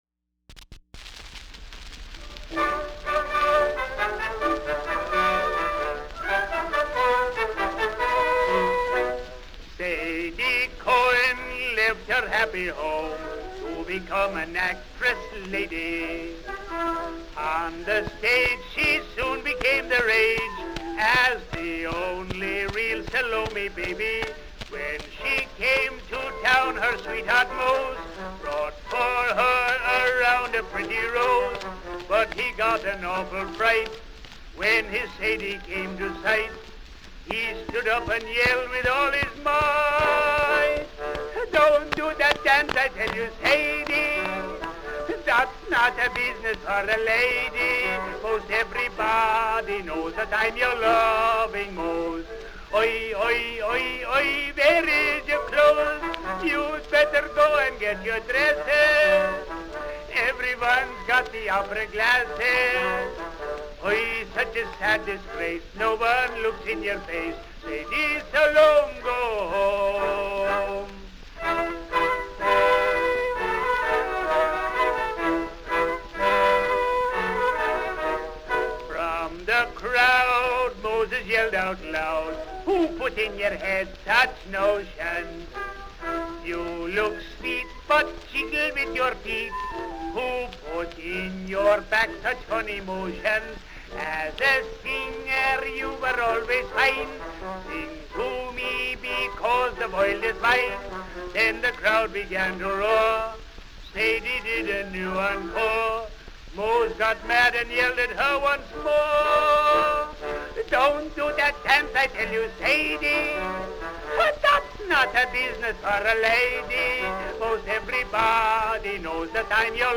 Popular music--1901-1910
phonograph cylinder